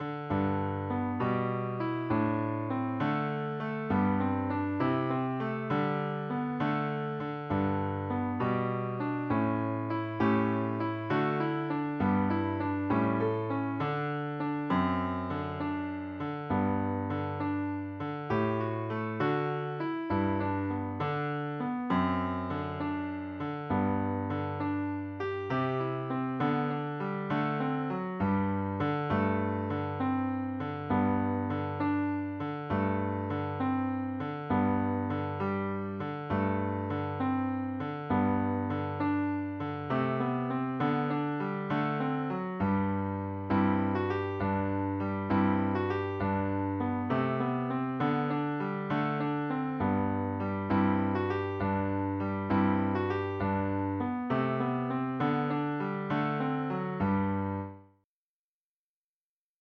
La sua musica, facile e ad effetto, è adatta a tutti.